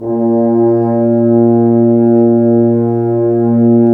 Index of /90_sSampleCDs/Roland LCDP06 Brass Sections/BRS_F.Horns 2 mf/BRS_FHns Dry mf